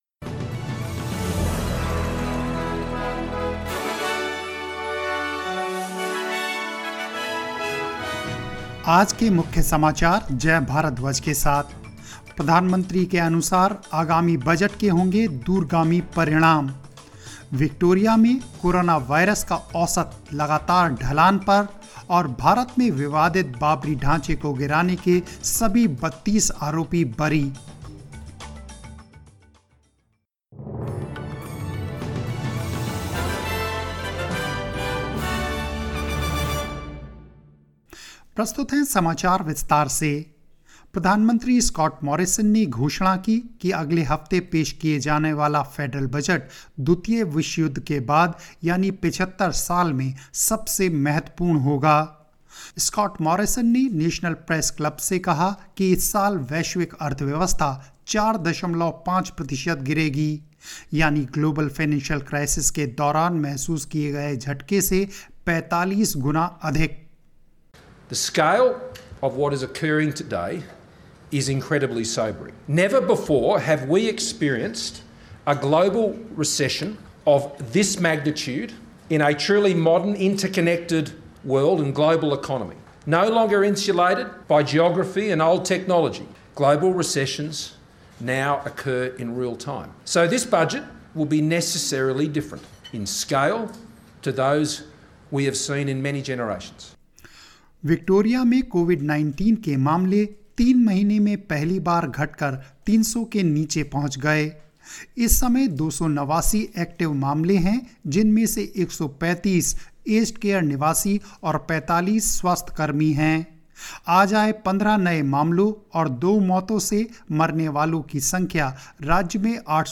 News in Hindi 01 October 2020